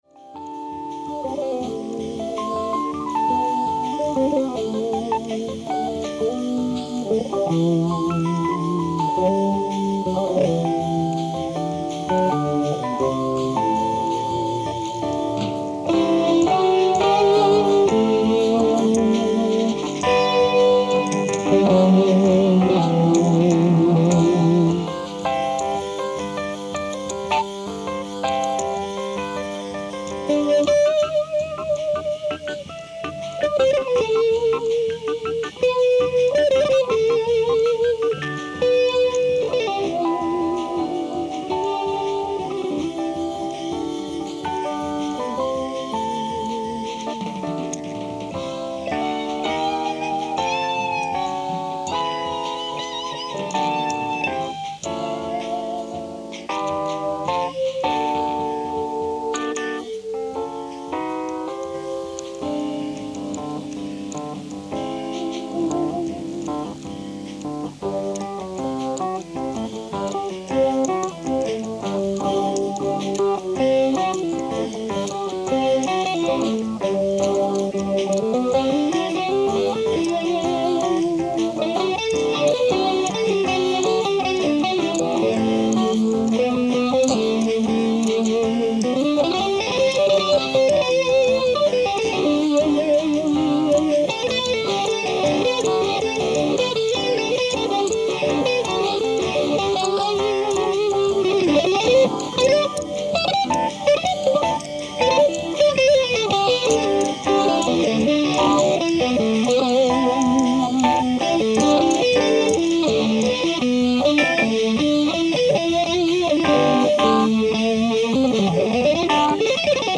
ライブ・アット・アートウッドホール、クラーク大学 03/11/1974
※試聴用に実際より音質を落としています。